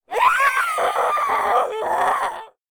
femalezombie_chase_03.ogg